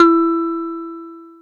JUP.8 E4   3.wav